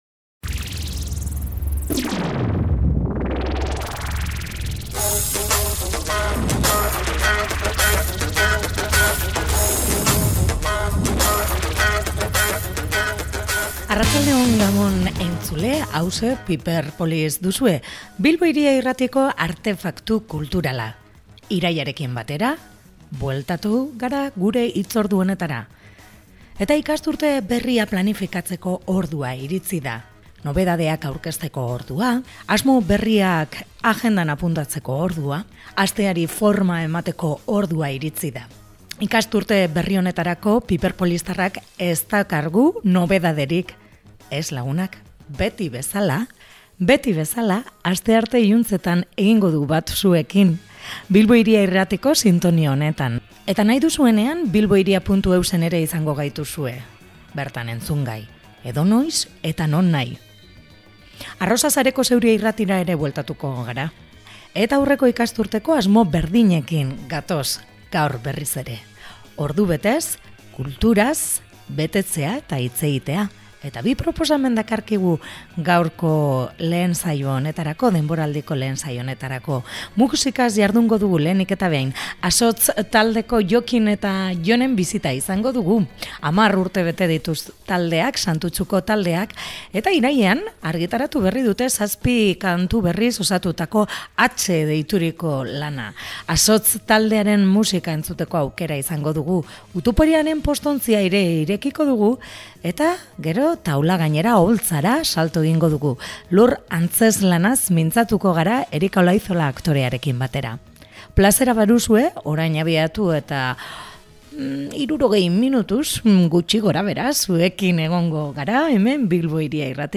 Irailarekin batera bueltan da Bilbo Hiria irratiko kultur artefaktua Piperpolis.